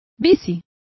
Also find out how bicis is pronounced correctly.